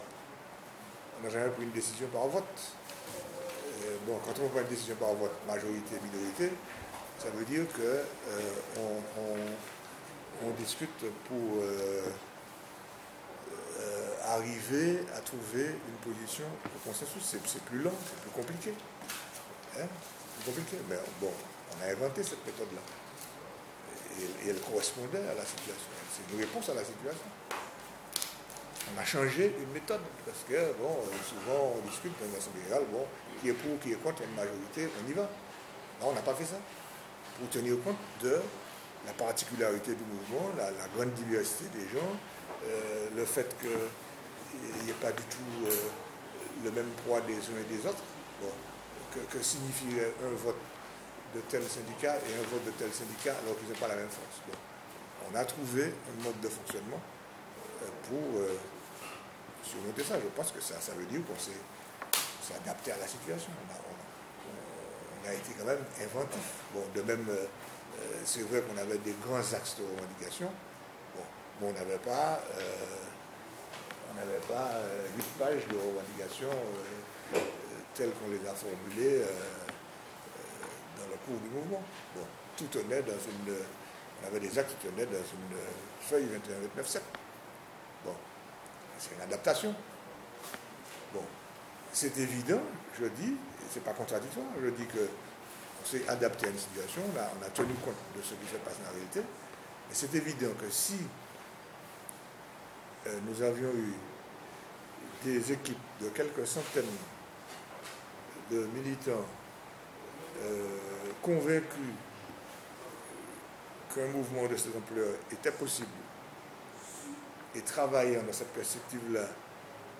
Interview à lire et à écouter.